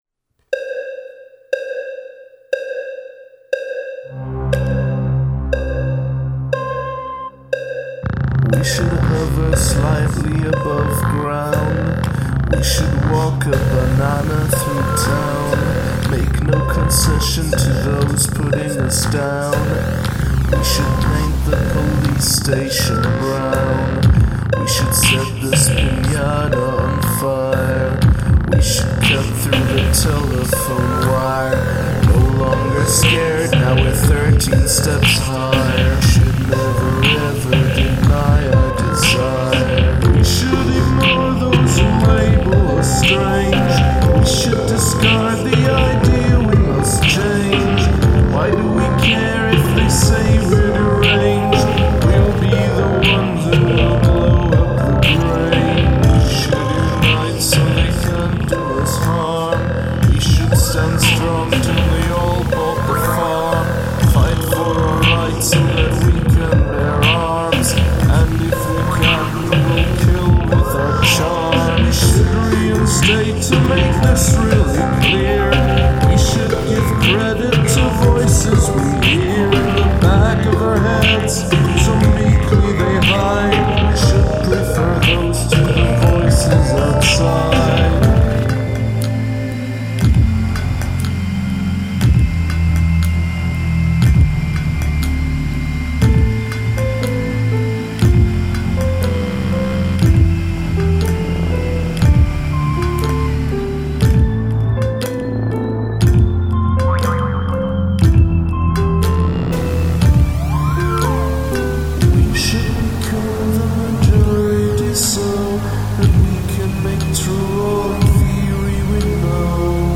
Have a guest play a household item on the track
Really excellent dark atmosphere.